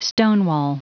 Prononciation du mot stonewall en anglais (fichier audio)
Prononciation du mot : stonewall